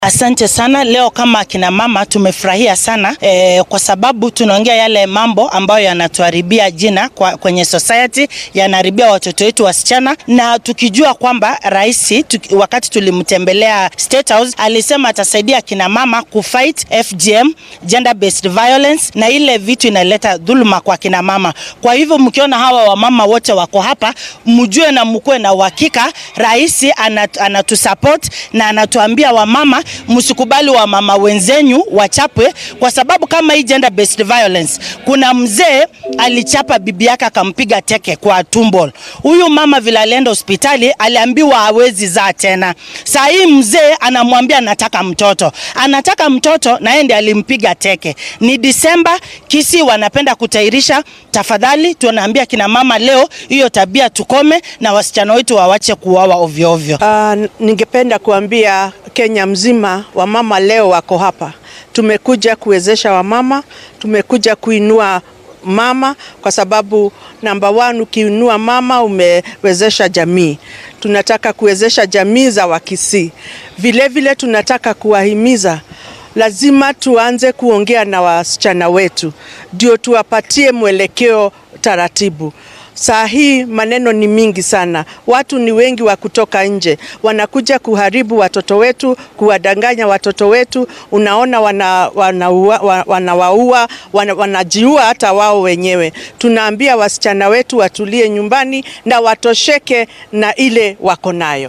Qaar ka mid ah hoggaamiyaasha haweenka ah ee ismaamullada dalka ayaa ku shiraya ismaamulka Kisii. Wakiilladan dumarka oo matalaya 18 ismaamul ayaa ka shiraya qorshaha ku aadan sida lagu soo afjaraya tacaddiga jinsiyadeed ee (GBV) iyo dilalka haweenka. Qaar ka mid wakiillada haweenka ee shirkan ka qayb galaya ayaa warbaahinta u faahfaahiyay qodobbada ay ka hadlayaan.